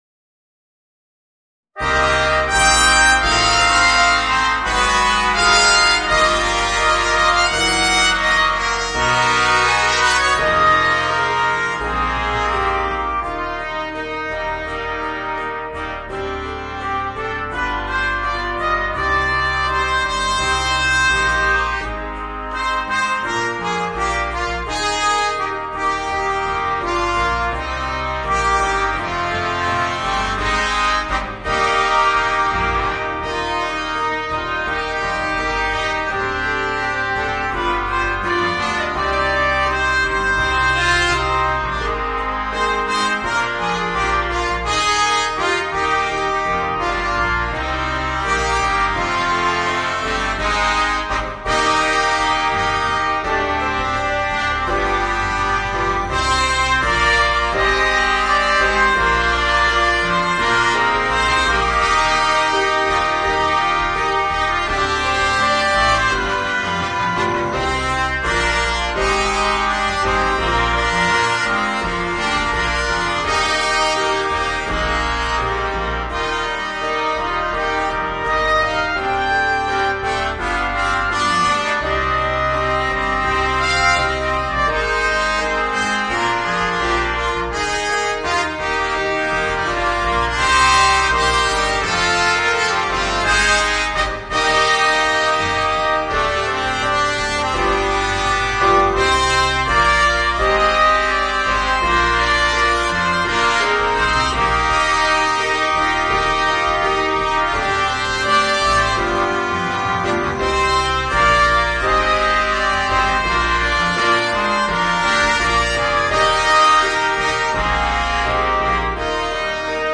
Voicing: 4 Trumpets and Piano